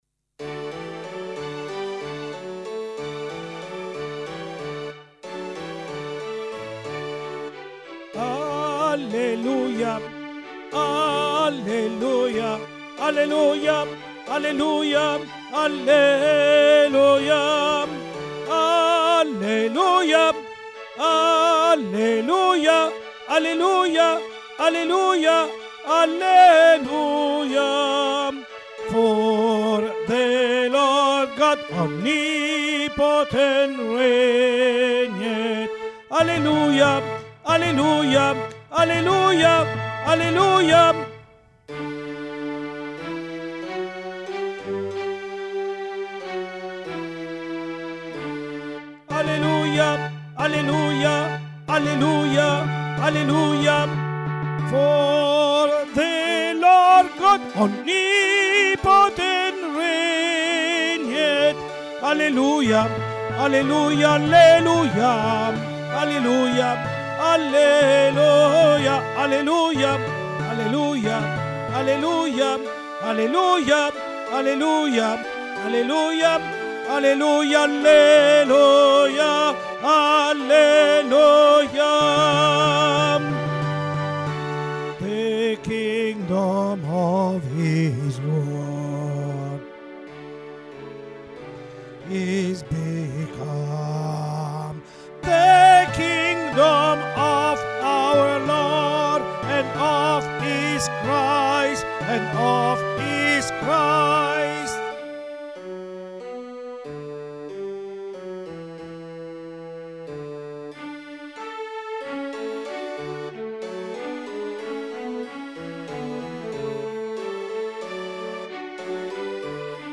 Soprani